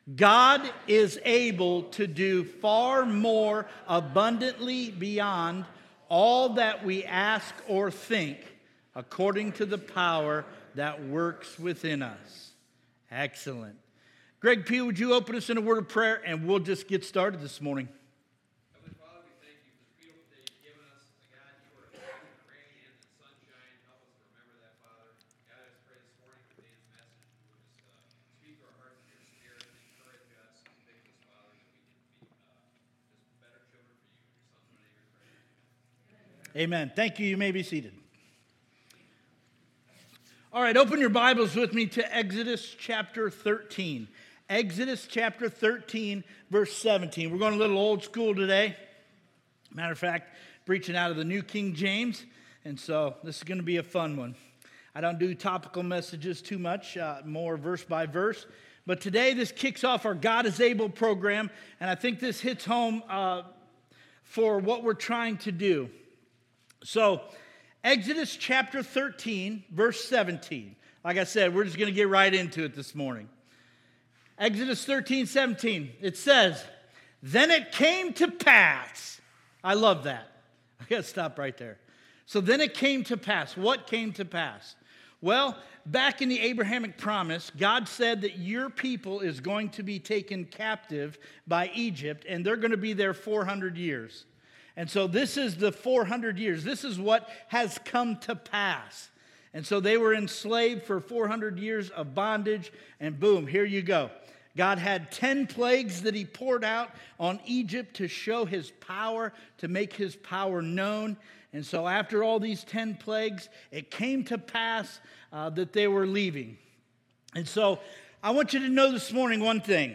Sermons Archive -